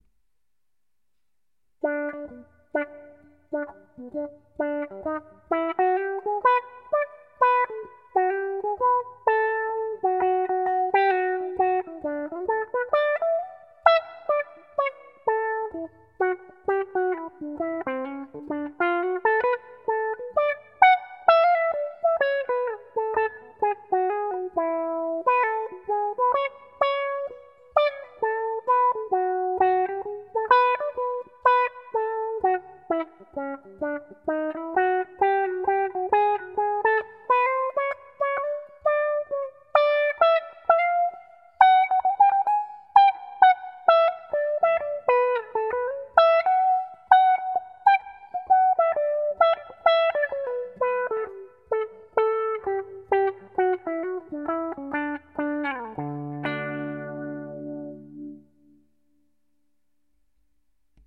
Mid Attack, low intensity:
low-resonance_-attack_slower_demo.mp3